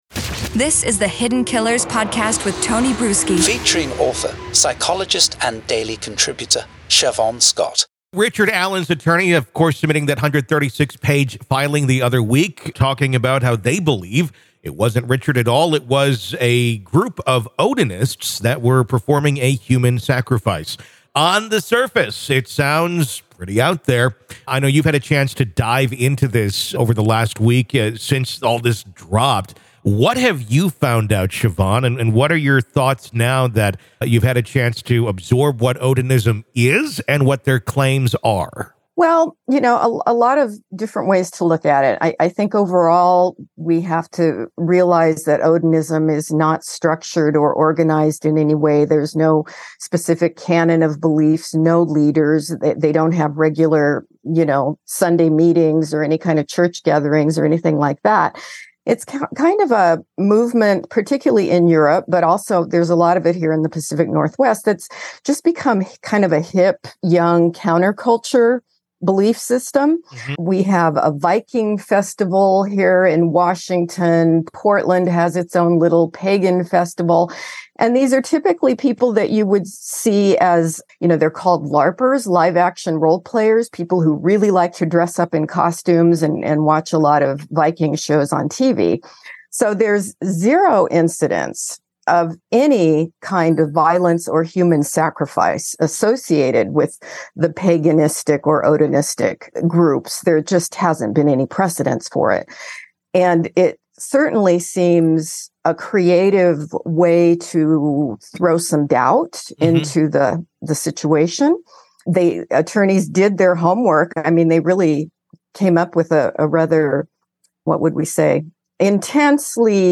Each episode navigates through multiple stories, illuminating their details with factual reporting, expert commentary, and engaging conversation.
Expect thoughtful analysis, informed opinions, and thought-provoking discussions beyond the 24-hour news cycle.